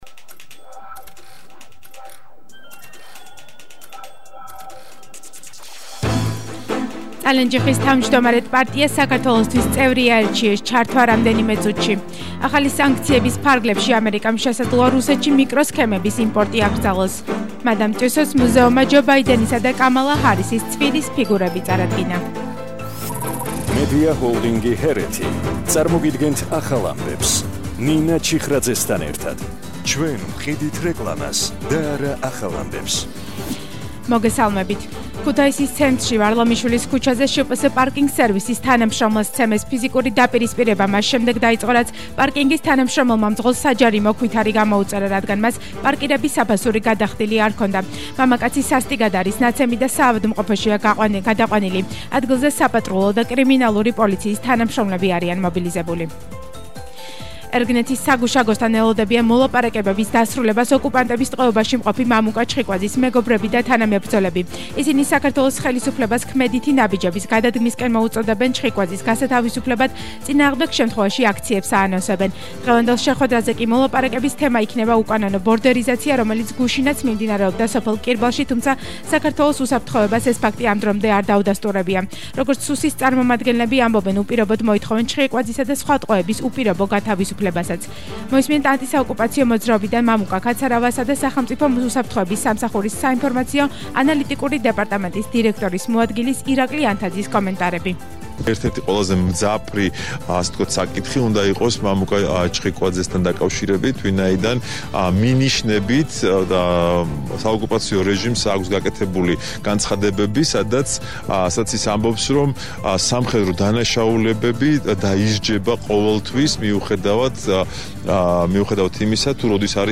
ახალი ამბები 14:00 საათზე – 20/01/22 - HeretiFM